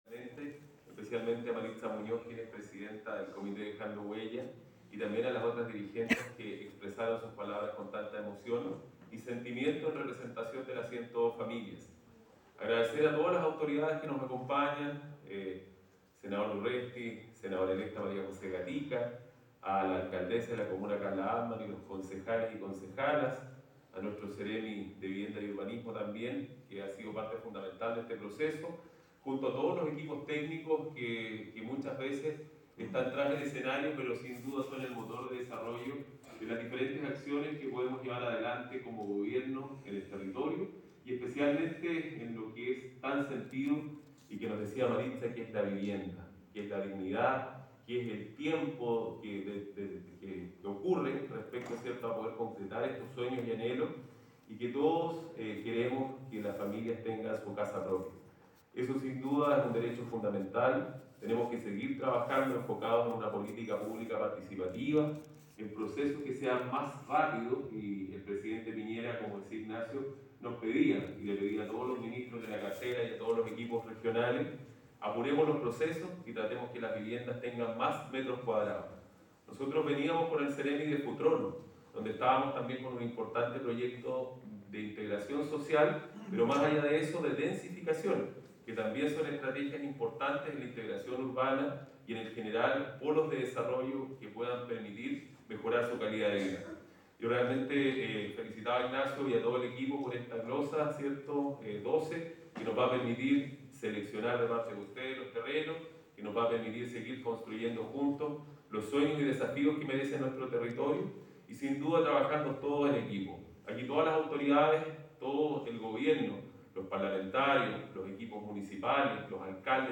La actividad que se desarrolló en el Teatro Lord Cochrane contó con la presencia del Delegado Presidencial, Cesar Asenjo; el senador, Alfonso de Urresti; la senadora electa, María José Gatica; la Gobernadora(s), Paz de La Maza; el Seremi de Vivienda y Urbanismo, Ignacio Vidal; la Alcaldesa de Valdivia, Carla Amtmann; junto a las dirigentes y beneficiarios.
delegado presidencial
delegado-presidencial.aac